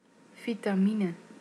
Ääntäminen
UK : IPA : /ˈvɪt.ə.mɪn/ US : IPA : /ˈvaɪ.tə.mɪn/